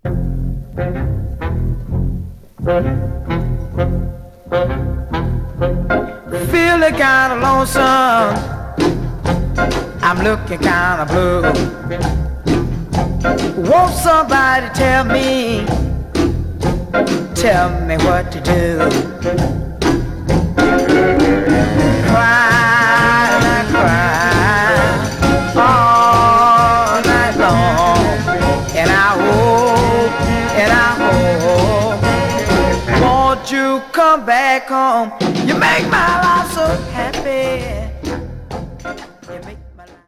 Genre: R&B, Soul/Funk